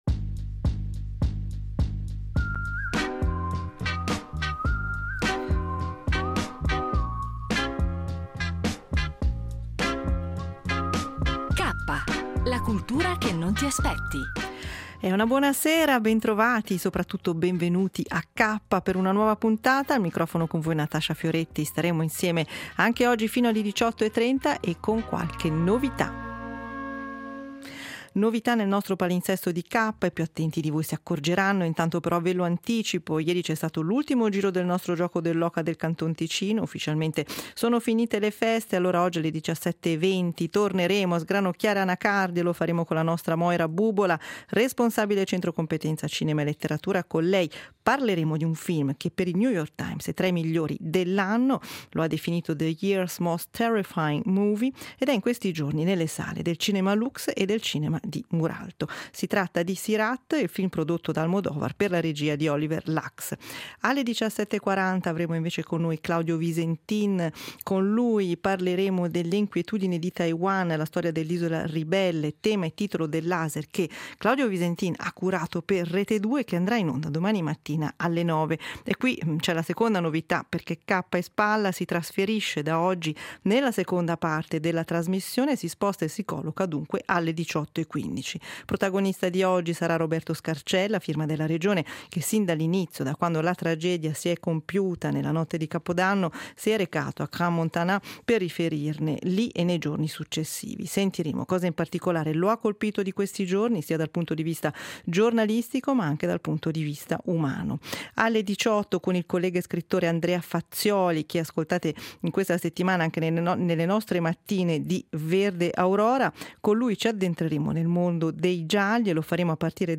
La puntata ha inoltre affrontato il tema dei ricci e proposto intermezzi musicali.